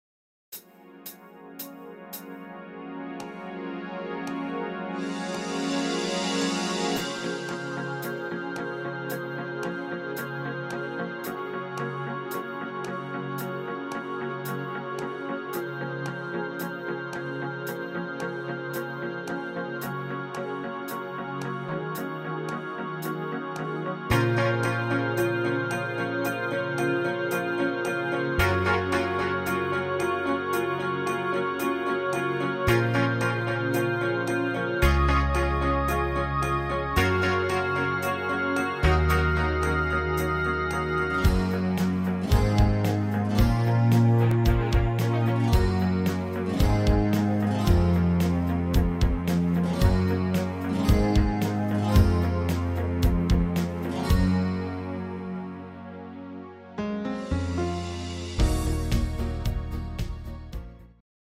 Rhythmus  Medium 8 Beat
Art  Duette, Englisch, Pop